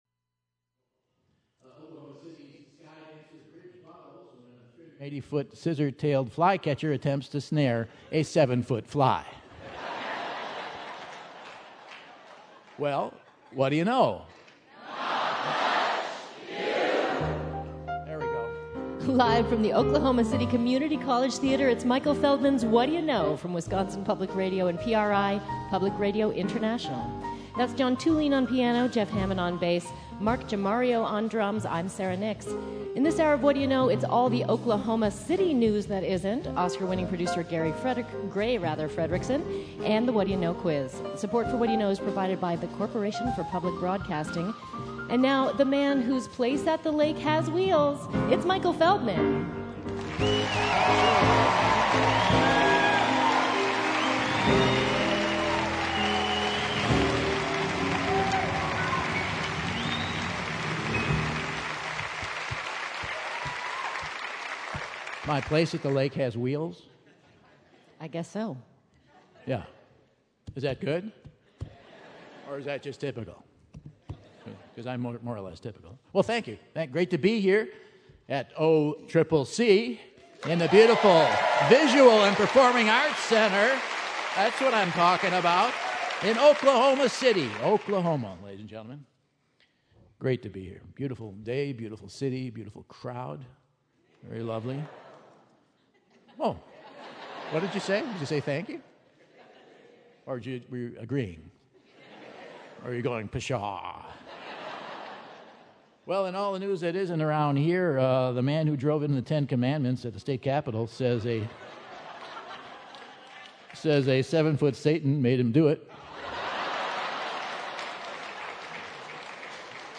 October 25, 2014 - Oklahoma City, OK - OCCC Visual and Performing Arts Center Theater | Whad'ya Know?